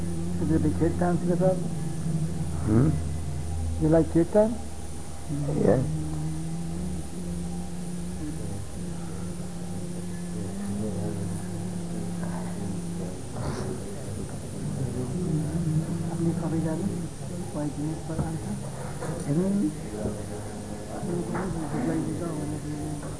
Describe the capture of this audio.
It is located at the end of tape #46 just before the kirtan begins.